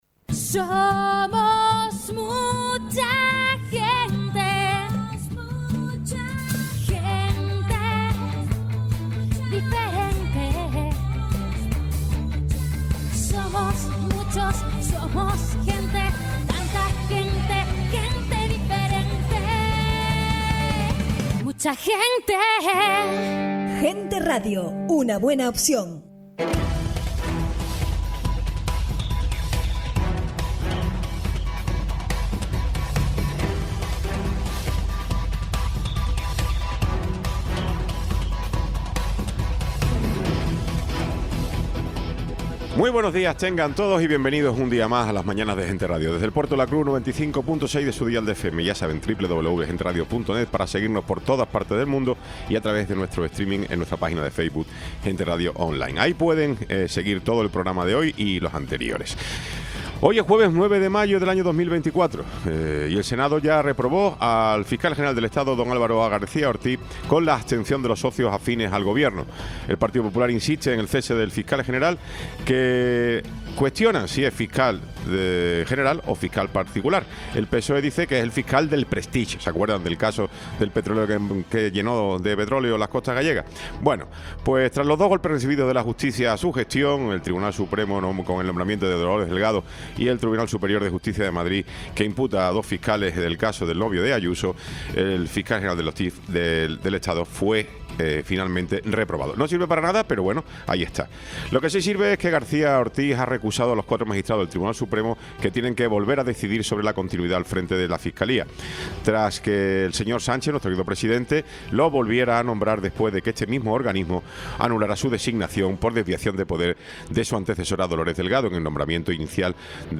Programa sin cortes